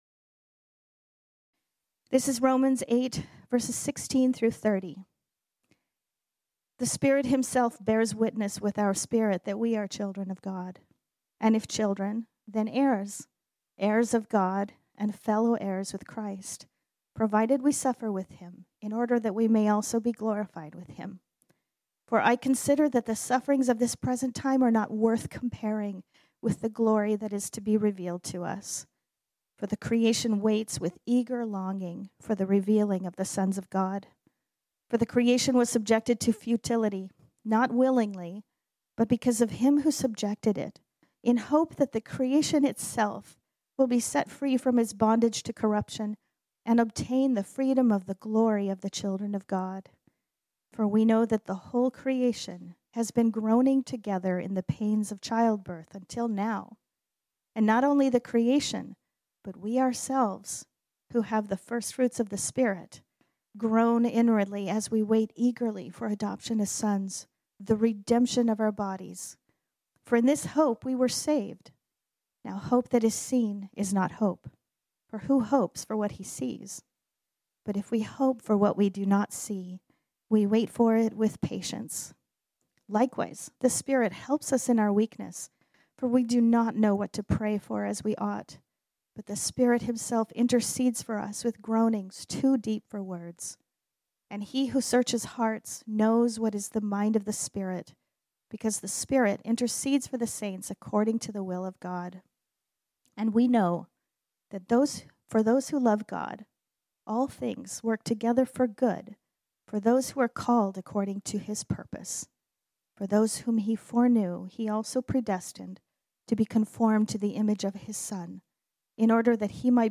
This sermon was originally preached on Sunday, May 9, 2021.